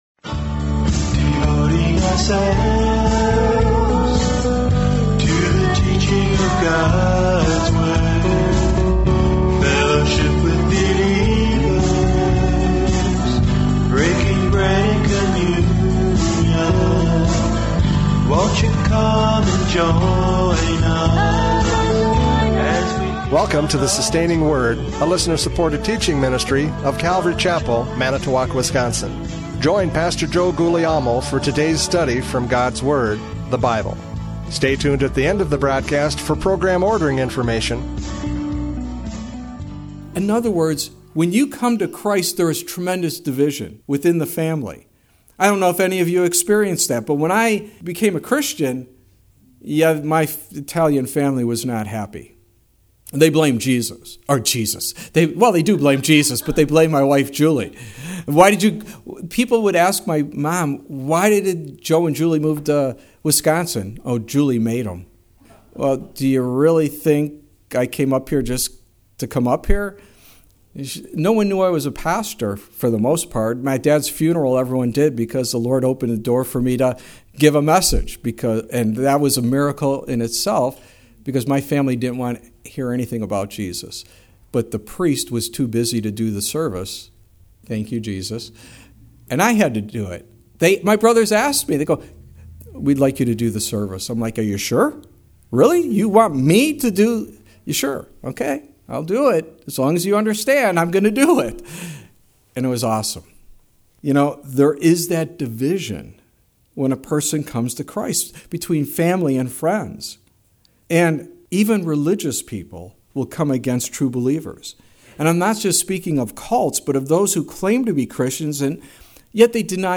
John 11:45-57 Service Type: Radio Programs « John 11:45-57 Blind Leaders!